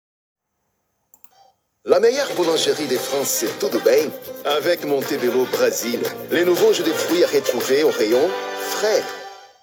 Voix off en français / Montebelo Brasil
- Baryton